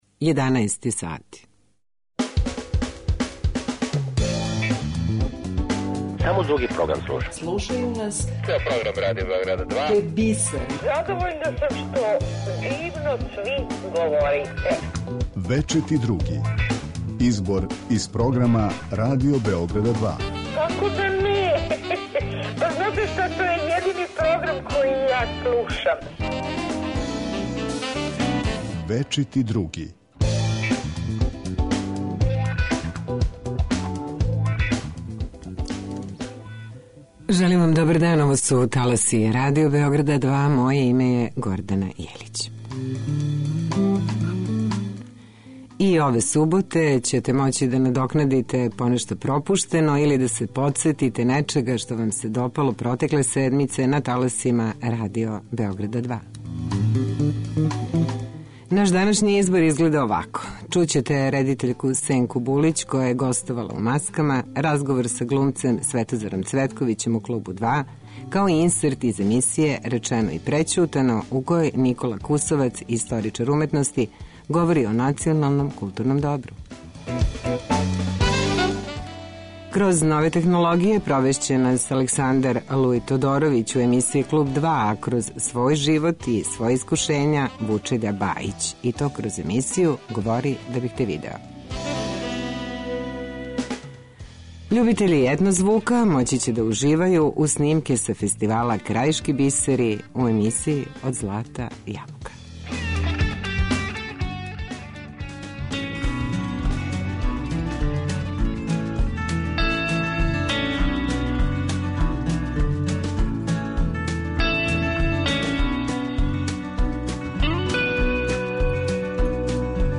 Избор из програма Радио Београда 2
Љубитељи етно звука моћи ће да уживају уз снимке са фестивала „Крајишки бисери", из емисије Од злата јабука .